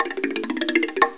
1 channel
afrorif2.mp3